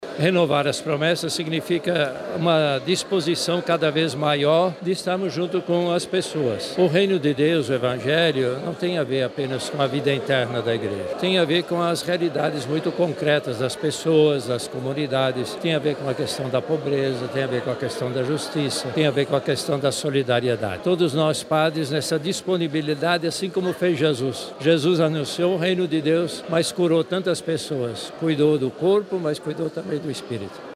SONORA_CARDEAL.mp3